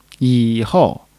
yi3 hou4.mp3